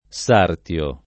DOP: Dizionario di Ortografia e Pronunzia della lingua italiana
sartiare